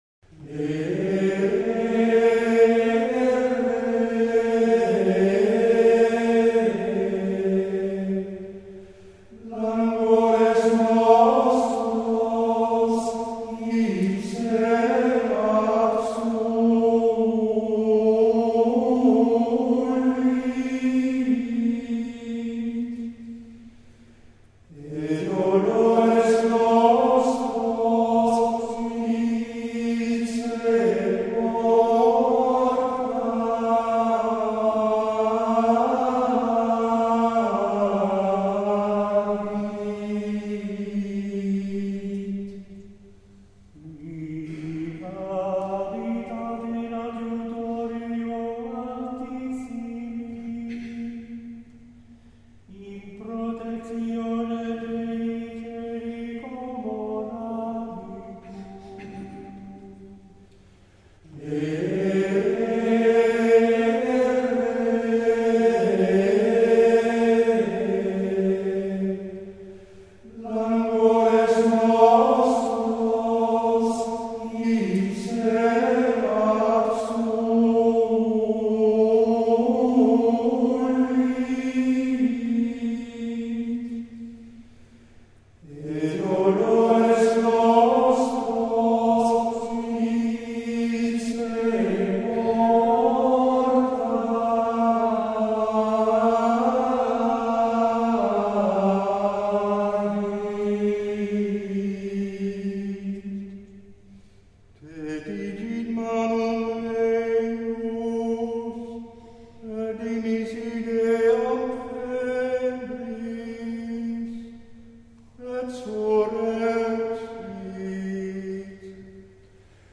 introïtus